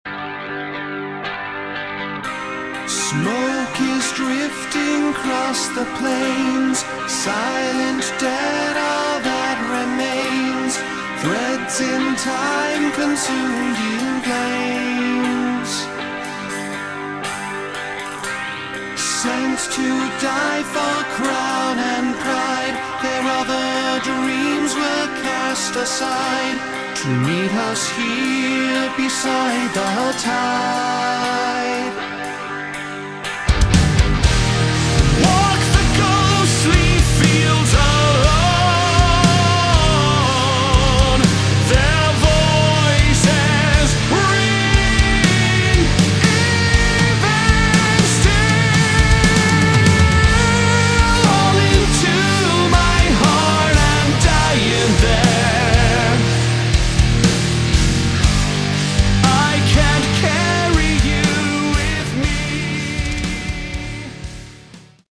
Category: Rock
bass guitar, backing vocals
drums, backing vocals
guitar, backing vocals
lead vocals, backing vocals